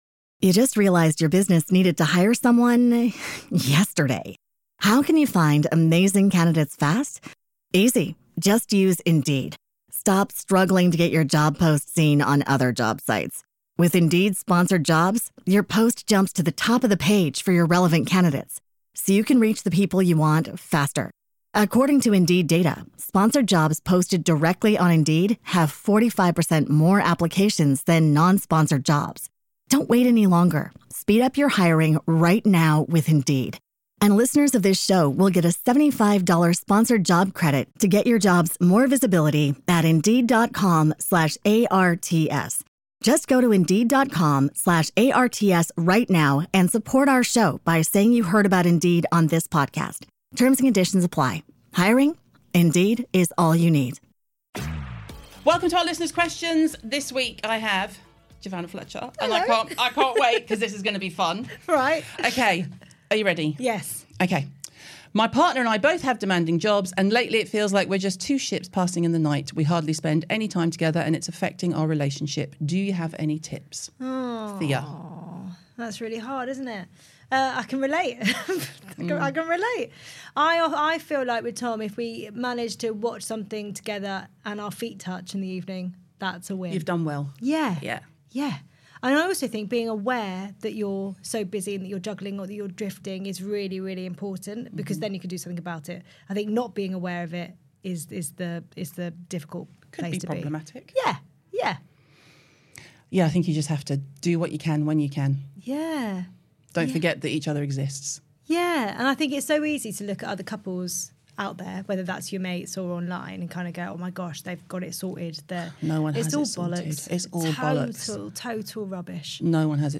Join Caroline Hirons, the UK’s leading skincare expert, and Giovanna Fletcher as they answer all your questions!